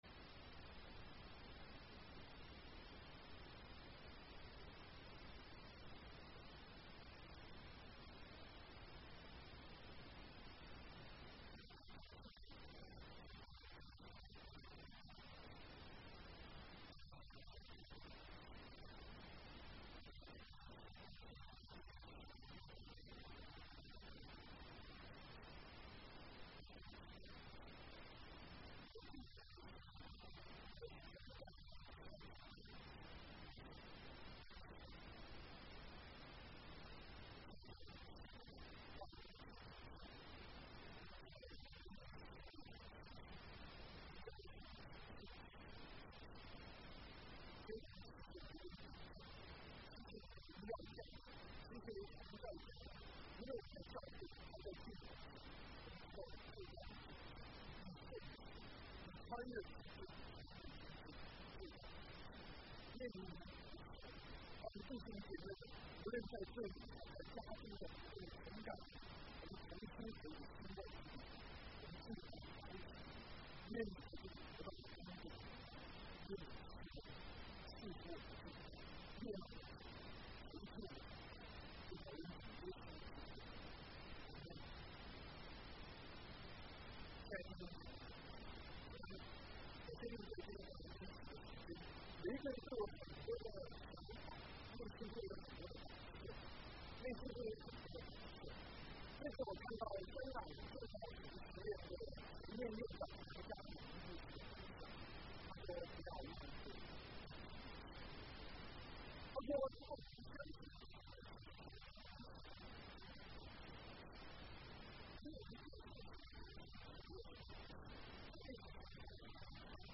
Sermons | South Gate Alliance Church | Mandarin (國語)